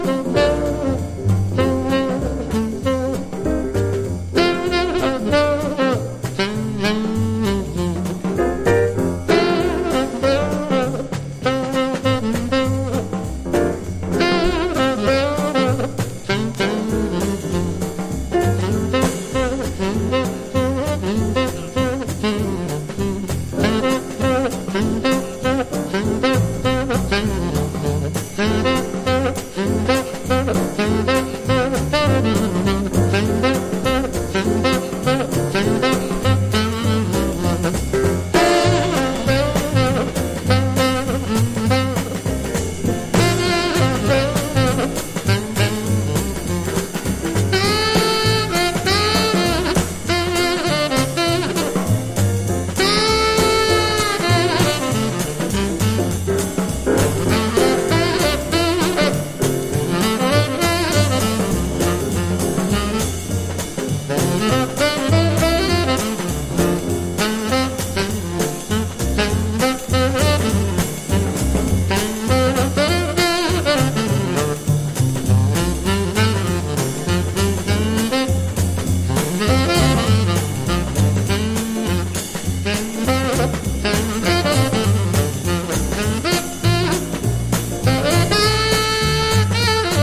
軽く伸びやかなブロウが気持ちよすぎる名曲
ジャズならではの叙情性が溢れまくってます。
MODERN JAZZ
所によりノイズありますが、リスニング用としては問題く、中古盤として標準的なコンディション。
（1963年プロモ盤　MONO 白ラベル　VAN GELDER　ゲートフォールド　カンパニースリーヴ）